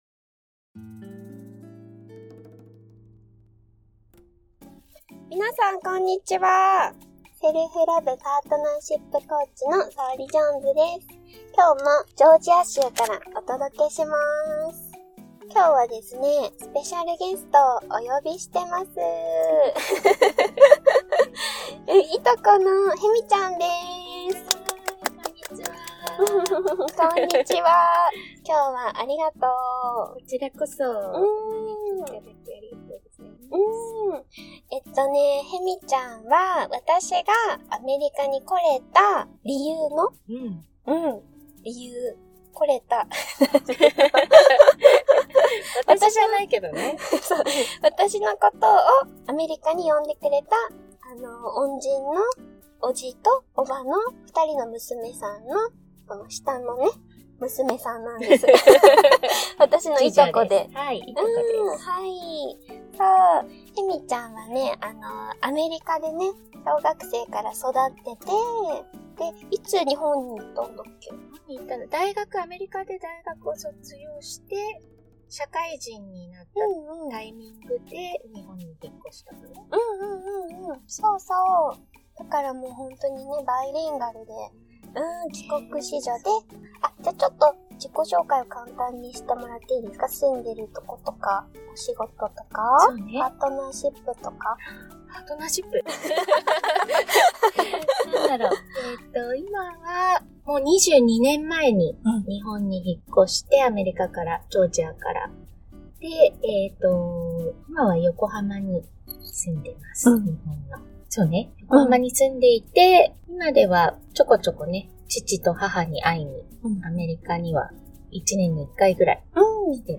ハッピーオーラ全開！